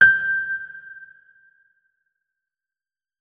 electric_piano